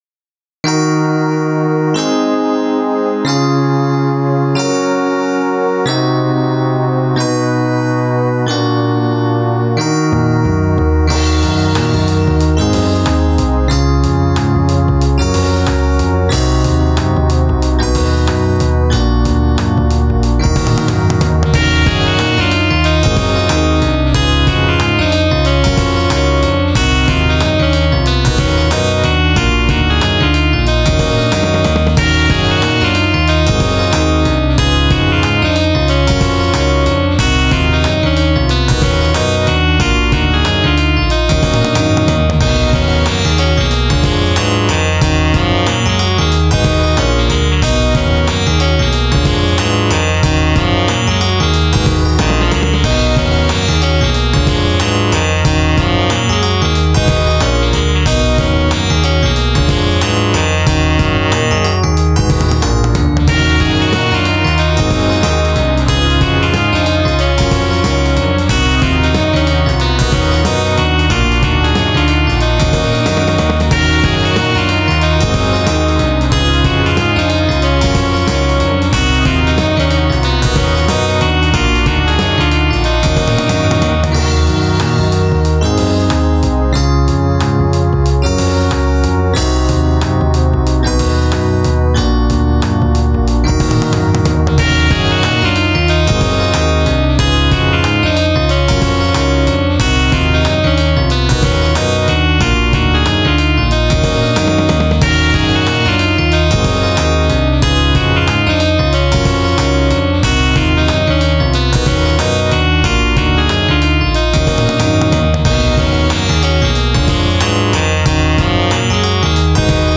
48kHz/24bit/ステレオ/wav
ジャズ
サックス
クール